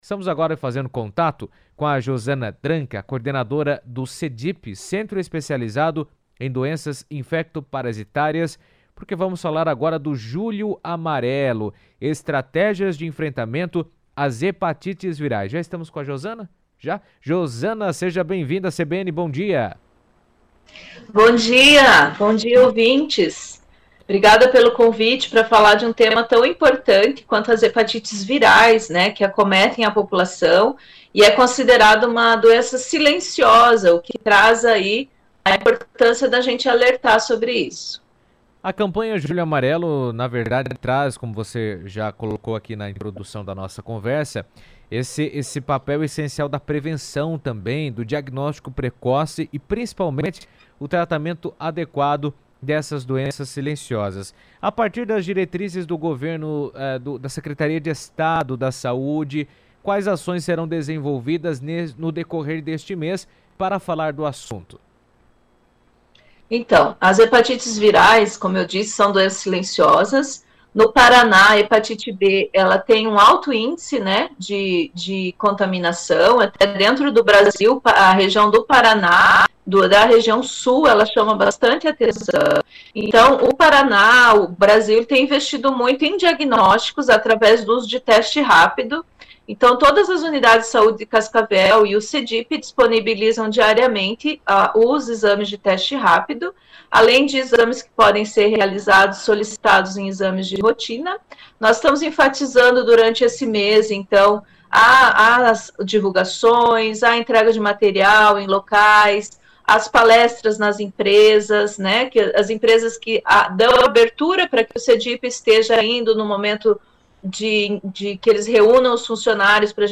abordou o tema em entrevista à CBN, reforçando o papel fundamental da conscientização para o controle e a redução dos impactos dessas enfermidades.